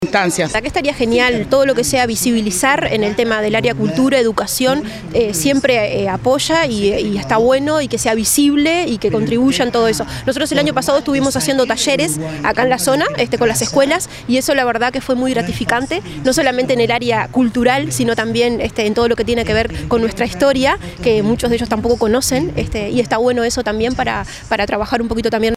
Se realizó el lanzamiento departamental del Mes de la Afrodescendencia en el Parque Roosevelt.